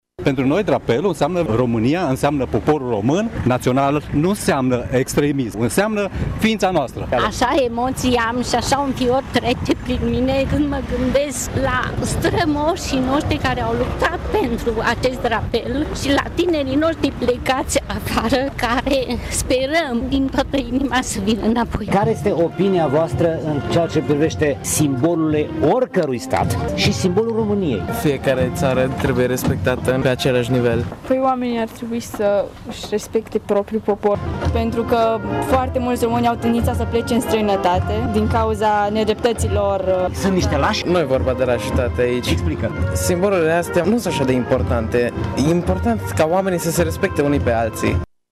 La cereremonia de azi din Cetatea Tîrgu-Mureșului au fost destul de puține persoane prezente, majoritatea fiind oficiali și persoane în vârstă.
Astel, dacă cei vârstnici s-au exprimat în favoarea unui patriotism sănătos, pentru unii tineri, sunt mai importante relațiile interumane decât simbolurile naționale: